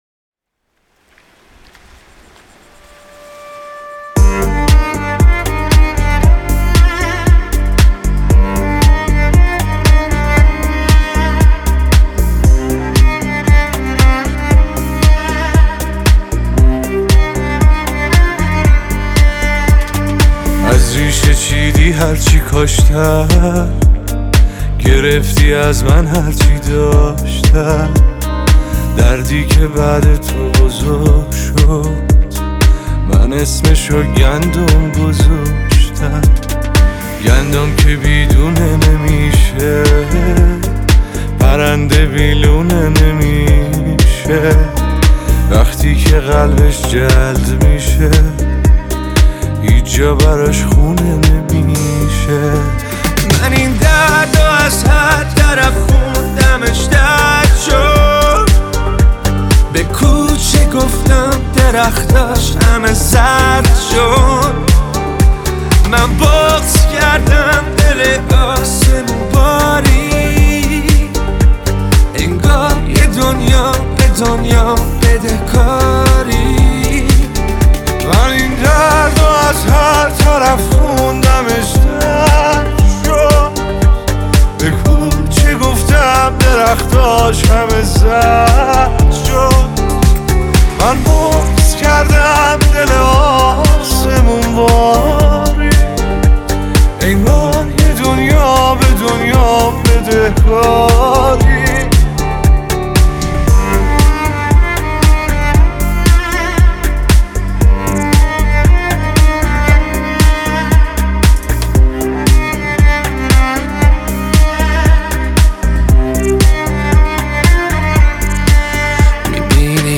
پاپ عاشقانه غمگین